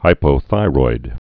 (hīpō-thīroid)